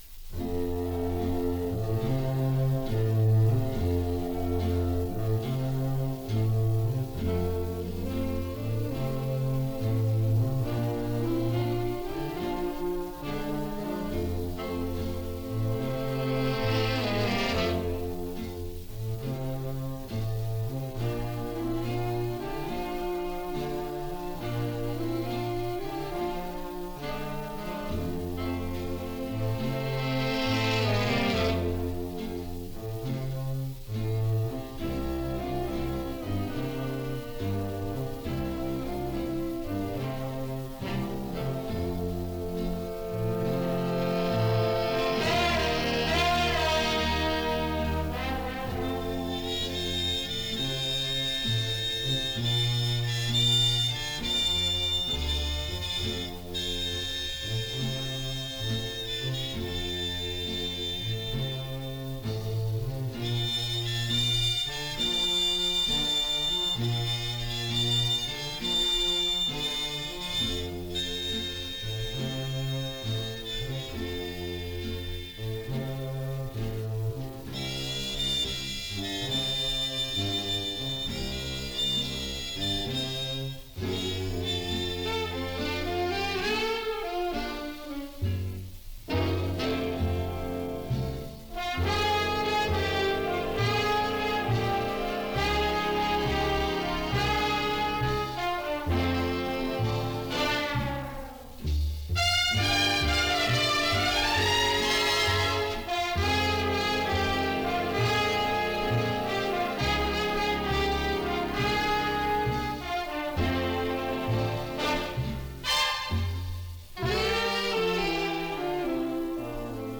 some 78 rpm sides from the late big band era